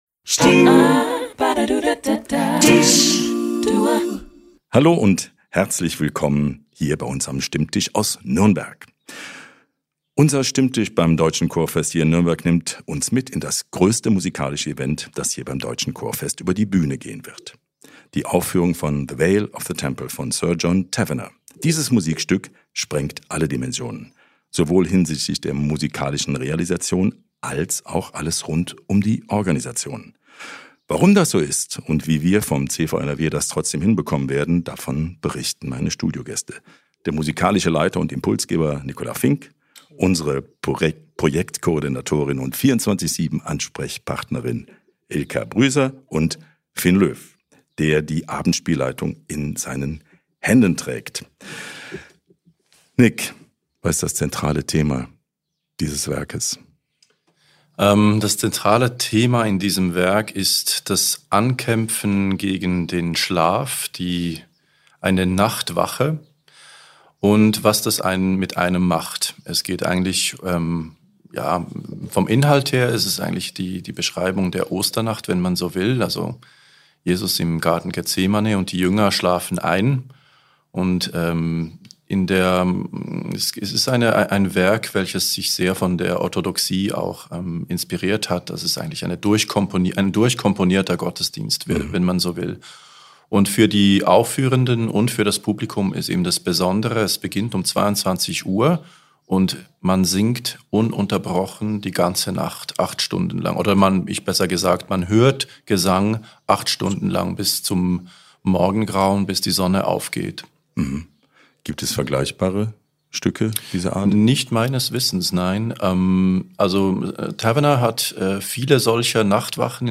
In dieser Episode werfen wir einen Blick hinter die Kulissen dieses einzigartigen Werkes, das selbst für das größte deutsche Chorfest eine ganz besondere Herausforderung darstellt. Unsere Studiogäste sind bestens informiert und klären all eure Fragen zu dieser beeindruckenden Aufgabe.